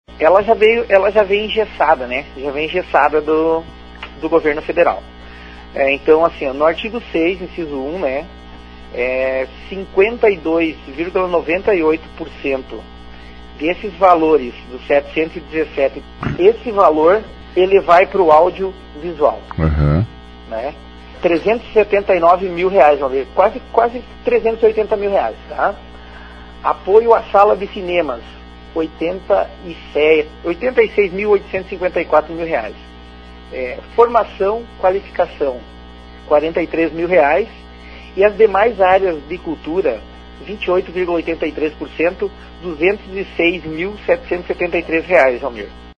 O secretário de Cultura, Esporte e Turismo, Alisson Pisoni, disse hoje na Rádio Repórter que o Conselho Municipal de Cultura realizou recentemente audiência pública para discutir a forma de encaminhamento das propostas.